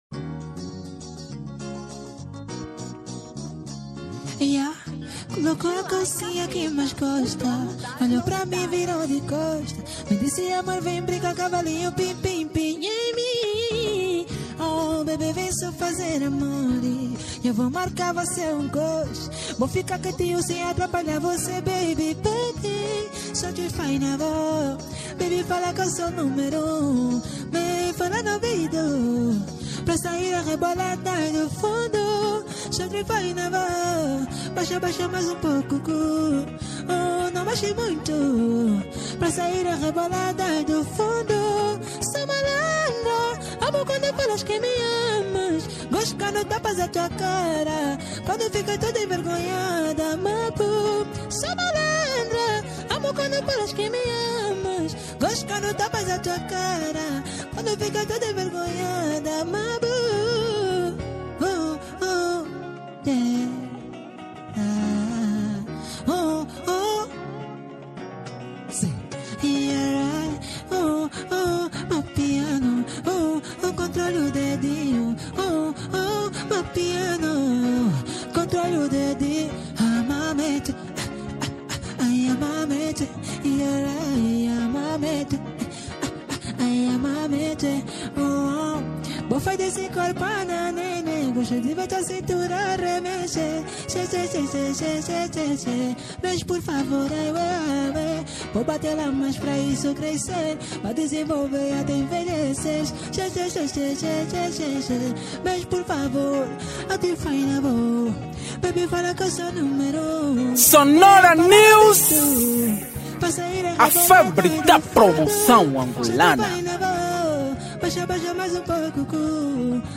Acústico 2025